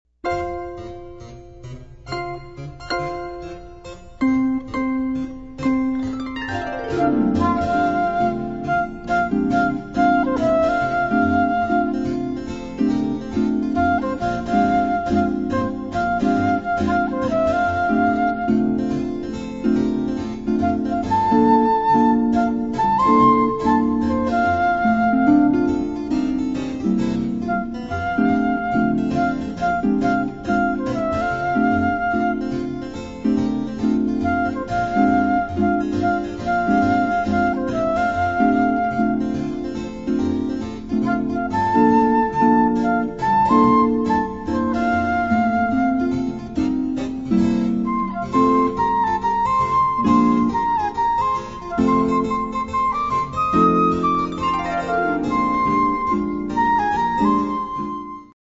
Genre: Jazz